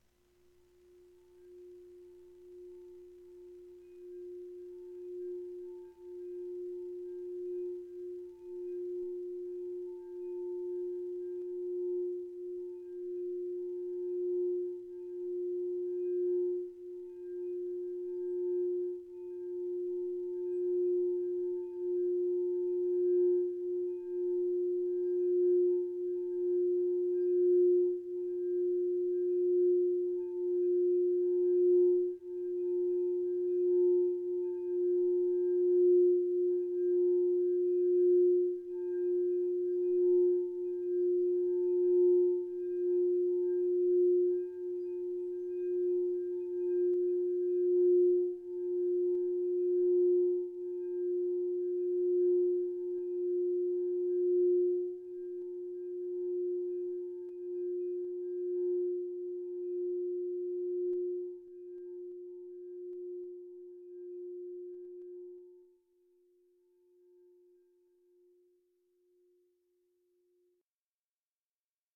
Eveil-Corpus-Cristal-vibrations.mp3